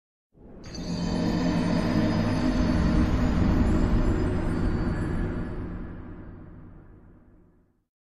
Dark Souls Death Sound Effect Free Download